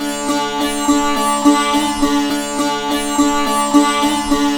105-SITAR1-L.wav